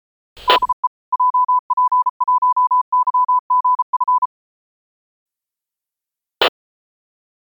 emoji1291.80MHz JP1YGF のID（2006年10月1日、記憶を元に再現したもの）